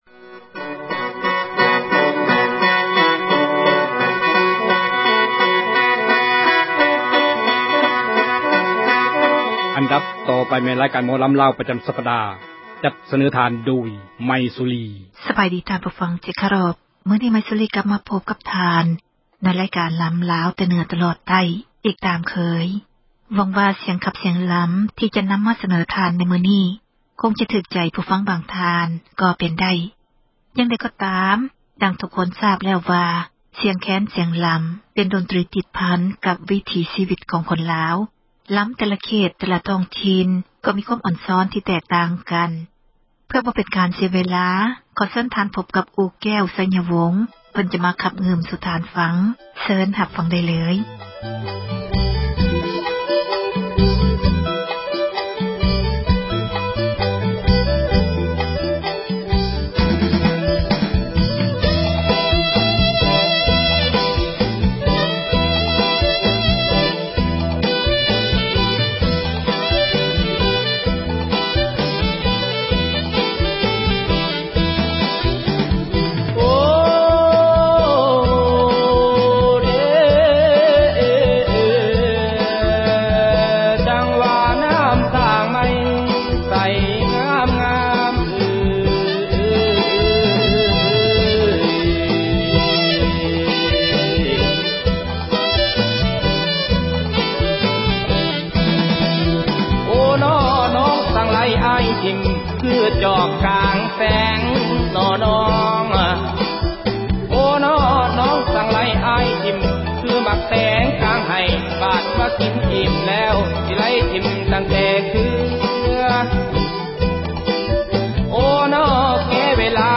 ຣາຍການ ໝໍລໍາລາວ ປະຈໍາສັປດາ ຈັດສເນີທ່ານ ໂດຍ